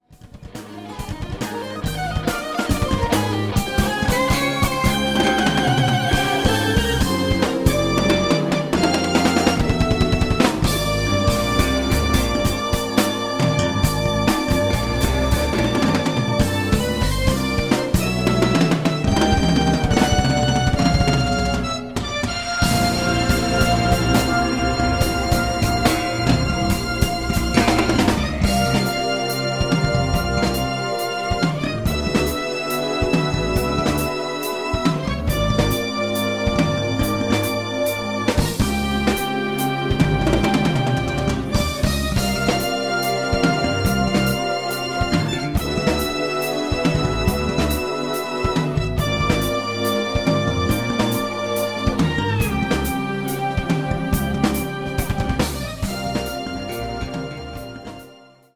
piano and synths
MIDI guitar
bass
drums